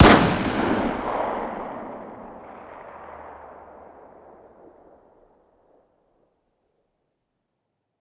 fire-dist-357sig-pistol-ext-04.ogg